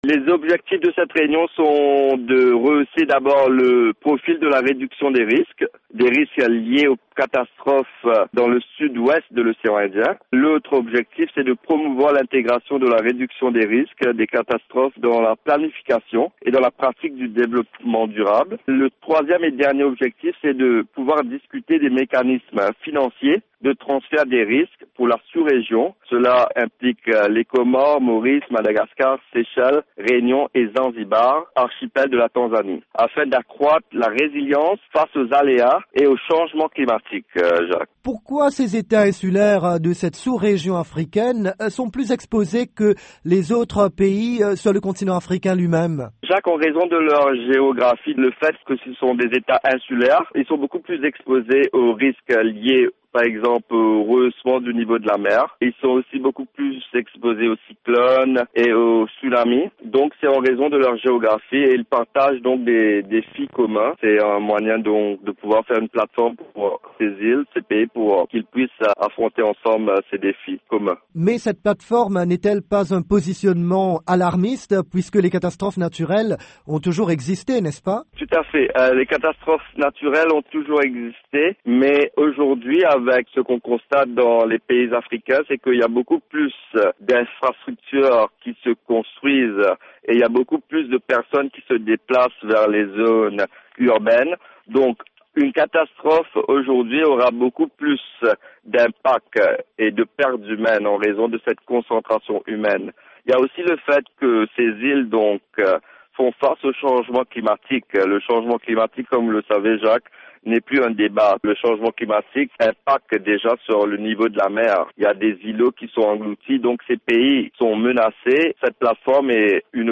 Dans une interview accordée à la Voix de l’Amérique